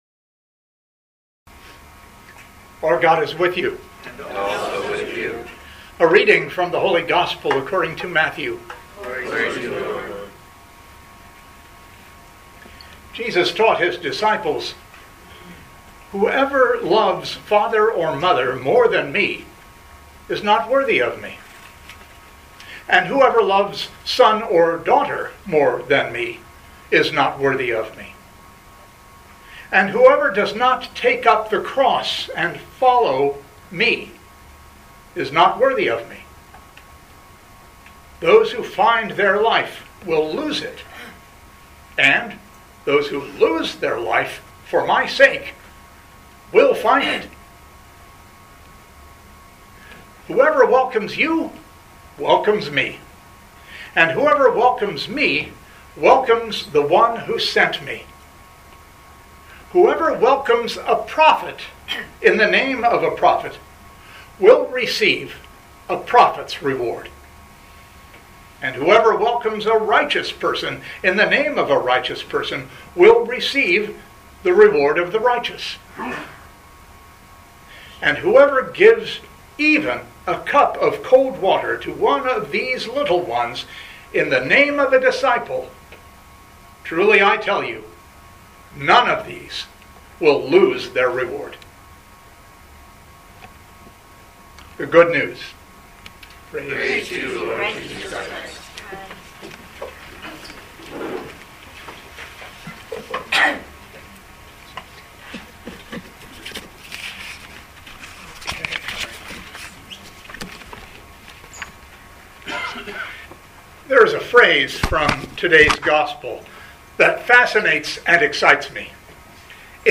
Living Beatitudes Community Homilies: Prophets Reward Is New Life